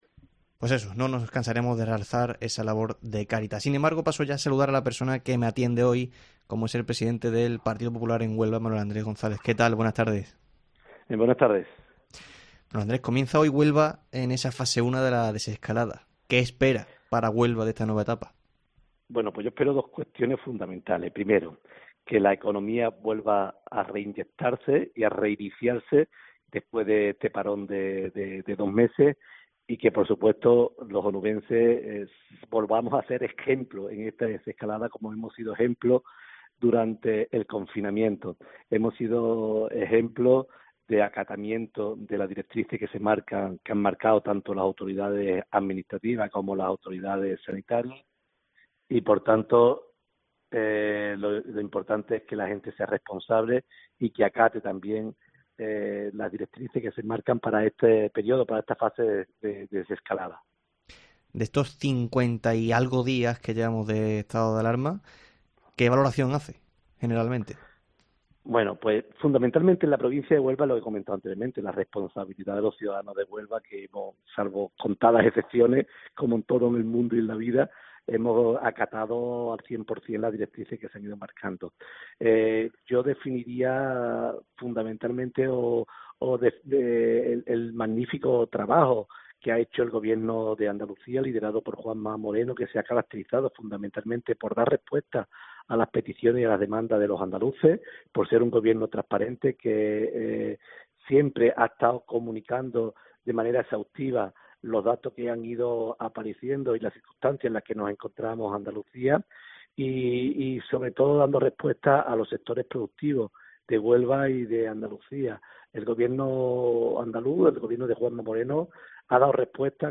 El presidente del Partido Popular en Huelva, Manuel Andrés González, nos ha atendido en el tiempo local de Herrera en COPE para realizarnos una valoración sobre la crisis.